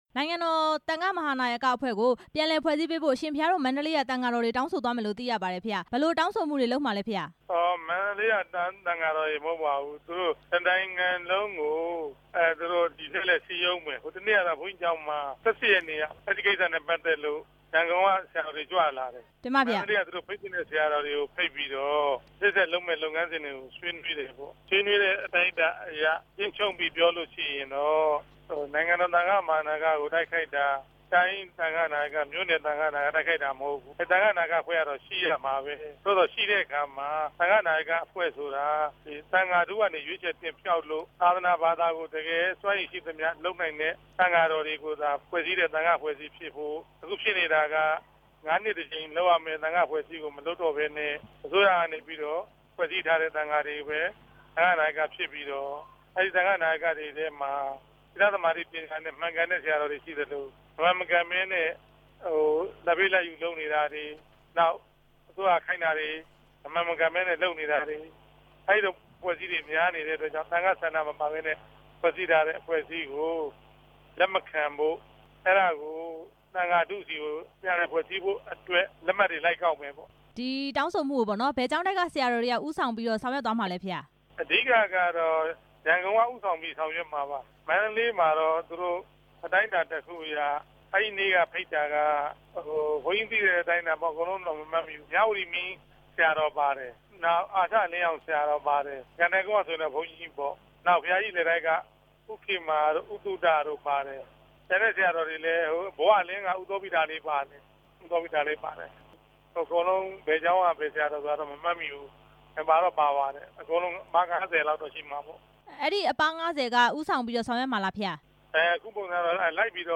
သံဃာ့လက်မှတ်တွေ စုစည်းကောက်ယူမယ့်အကြောင်း မေးမြန်းချက်